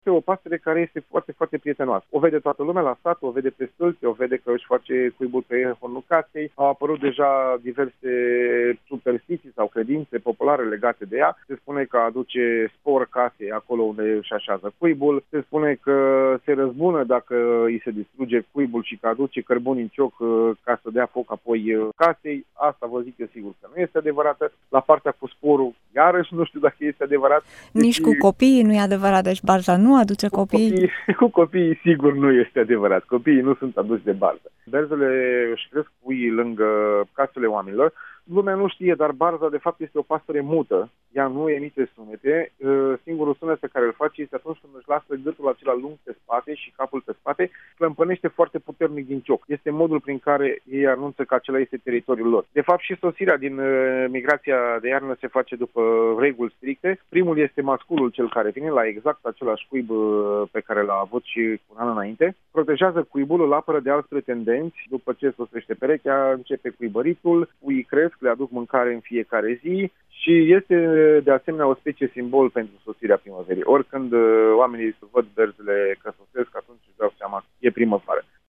Emisiunea Drum cu Prioritate vă prezintă o pasăre călătoare pe zi.